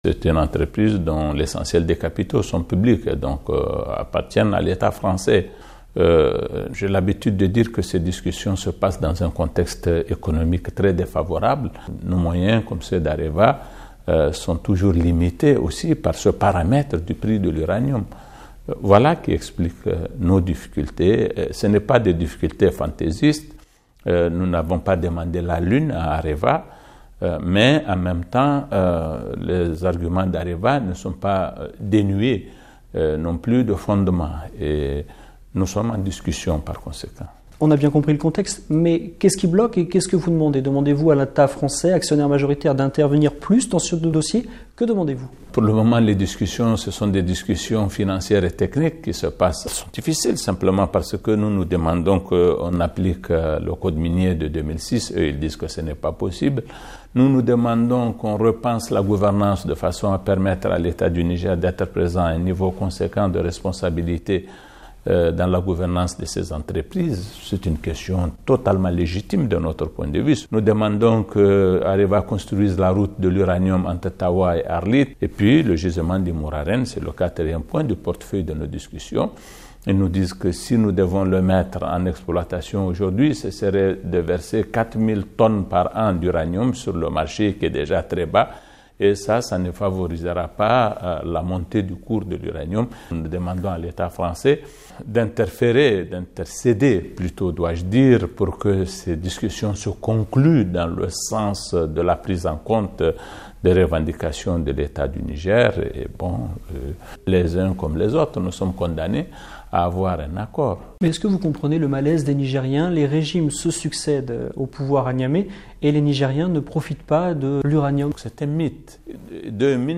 Invité de la Voix de l'Amérique, le ministre nigérien des affaires étrangères revient sur l'insécurité dans le Sahel et sur les difficiles négociations avec le géant français du nucléaire.